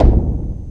Kick 14.wav